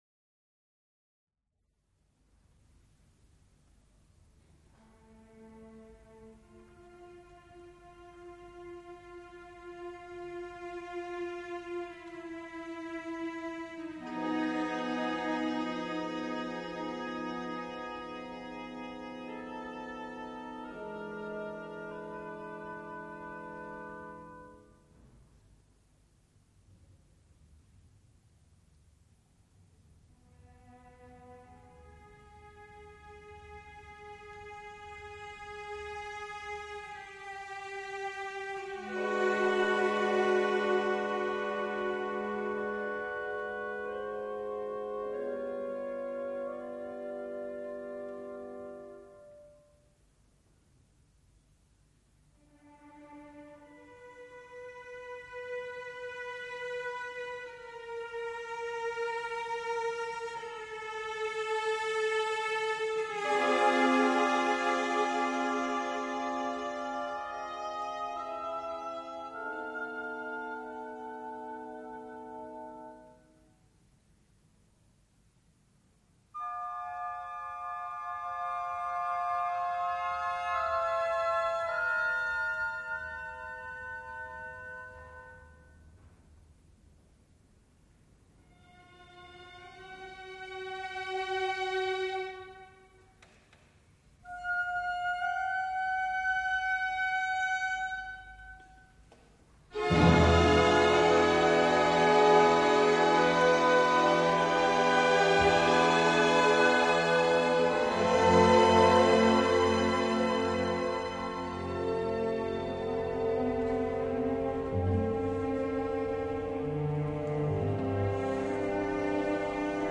三幕乐剧